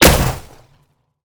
sci-fi_weapon_plasma_pistol_06.wav